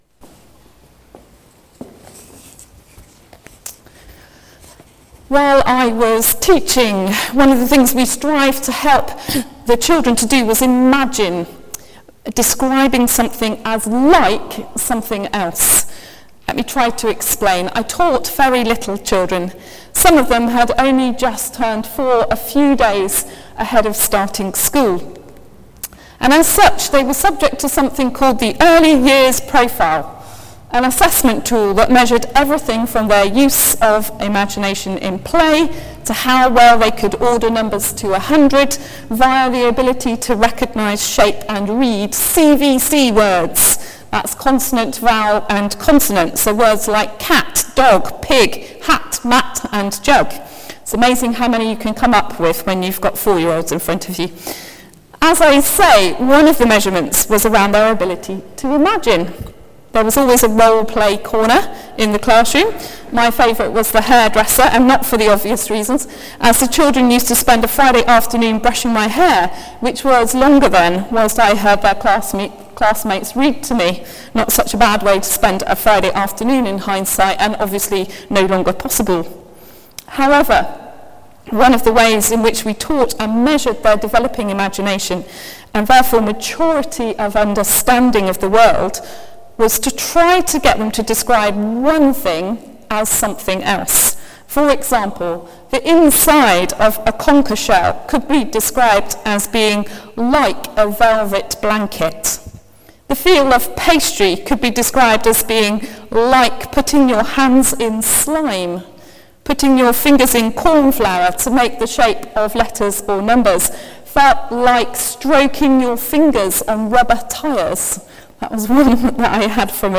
Sermon: The Kingdom of Heaven is Like……….